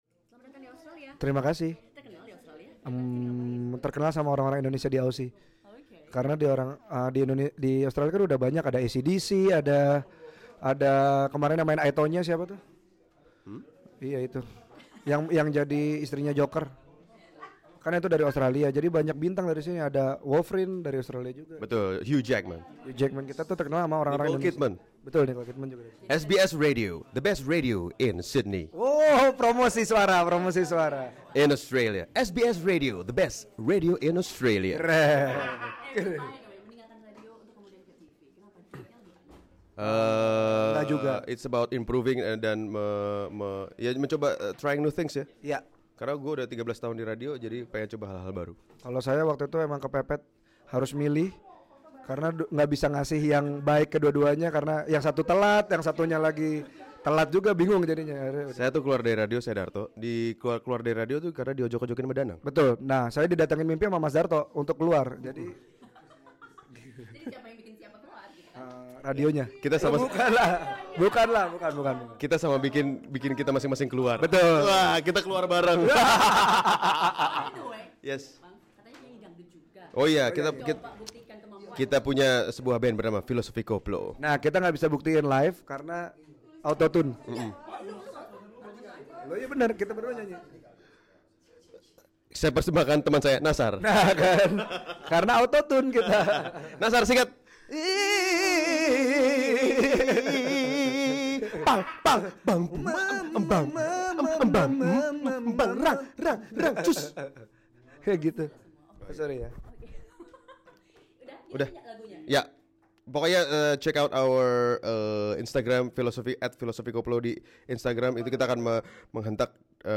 The duo - who was renowned for their program in a national TV in Indonesia - spoke to SBS Indonesian last Saturday.